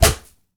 punch_blocked_04.wav